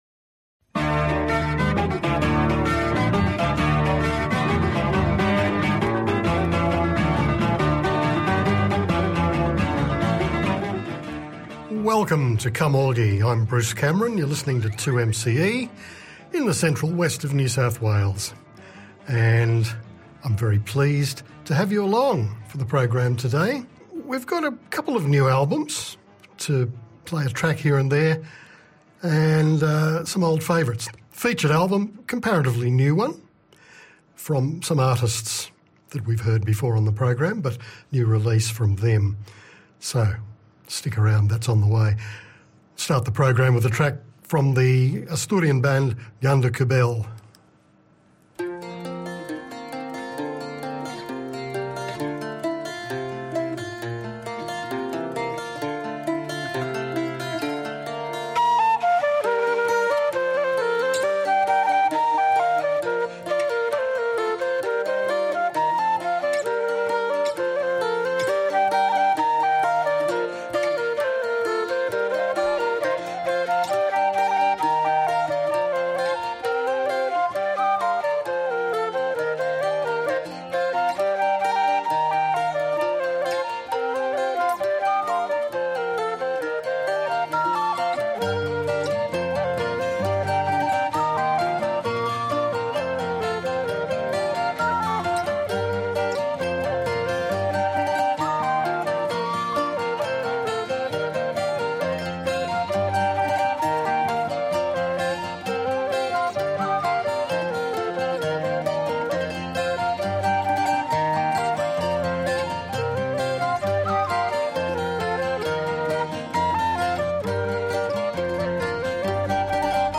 This is the duo's second album and includes twelve traditional songs beautifully arranged and performed.